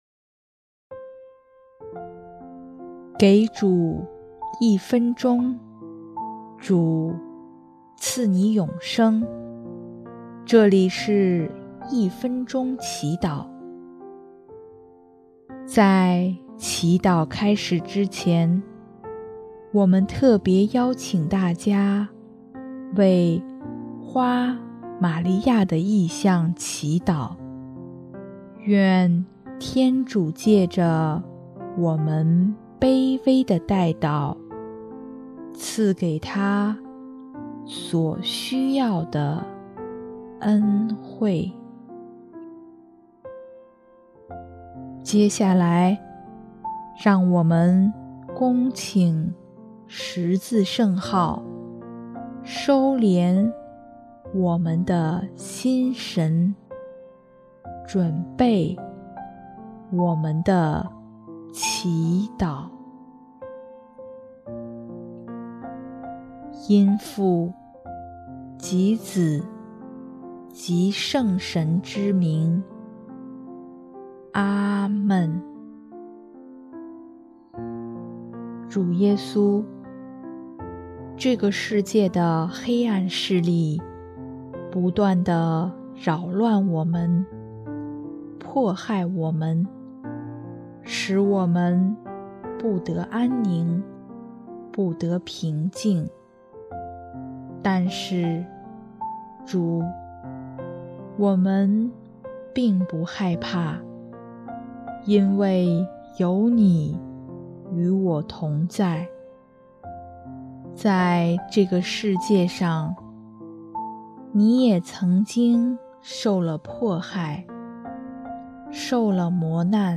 【一分钟祈祷】| 3月24日 有主就有一切